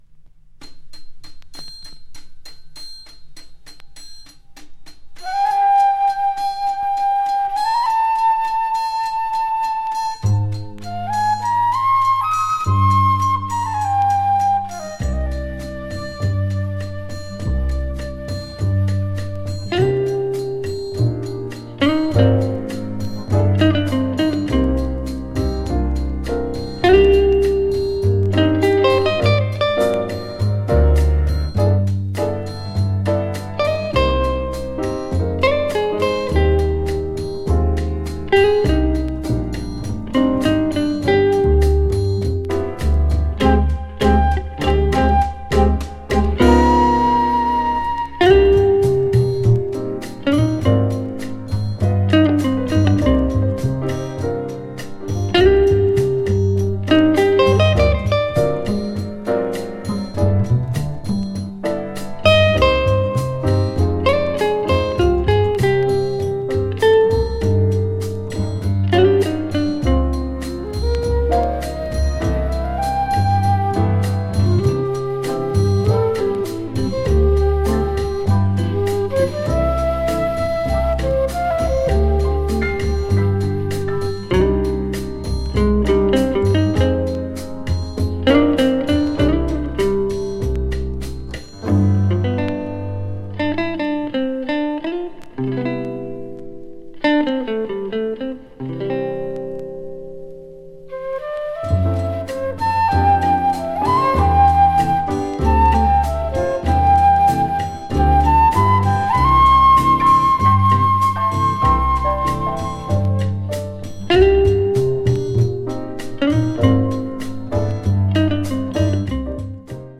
日本の伝統的な曲を取り上げた好作です。
秀逸なアレンジと演奏で多彩に聴かせます。